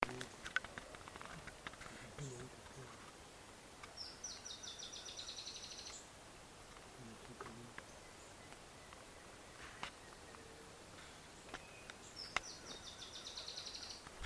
Dusky-tailed Antbird (Drymophila malura)
Location or protected area: Bio Reserva Karadya
Condition: Wild
Certainty: Recorded vocal
Tiluchi-Estriado.mp3